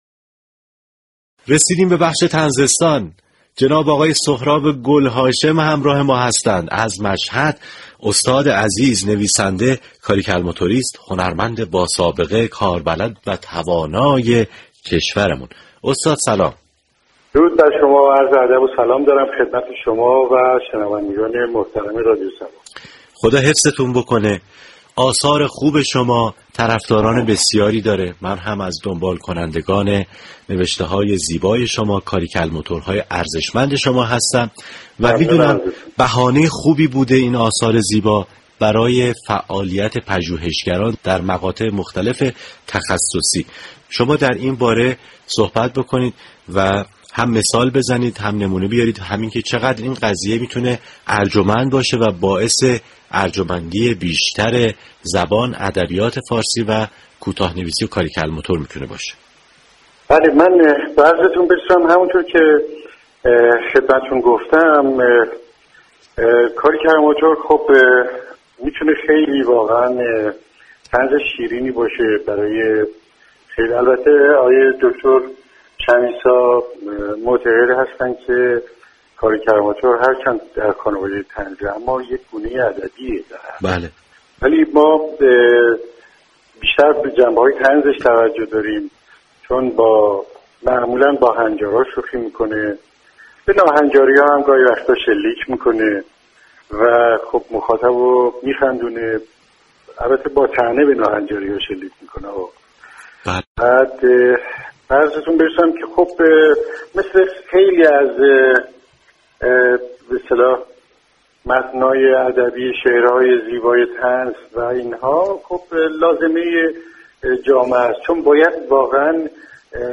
شنونده گفتگوی تلفنی رادیو صبا